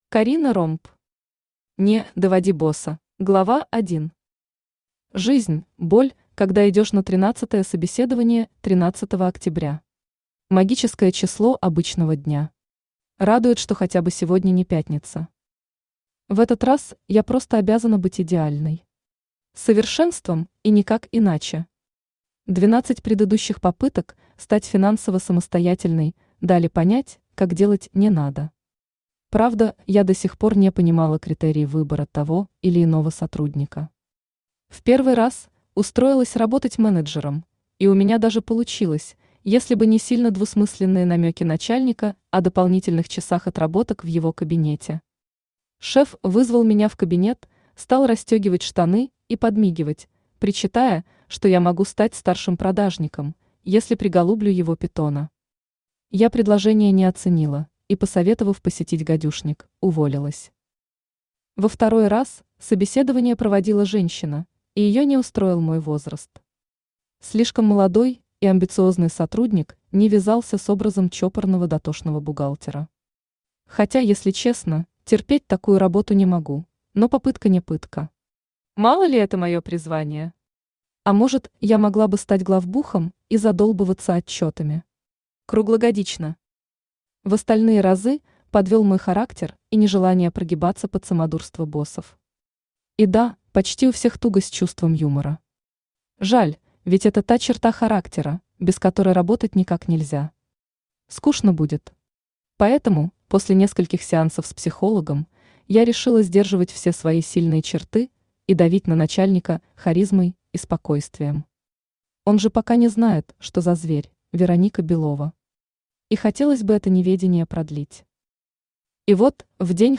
Аудиокнига (Не)Доводи босса | Библиотека аудиокниг
Aудиокнига (Не)Доводи босса Автор Карина Ромб Читает аудиокнигу Авточтец ЛитРес.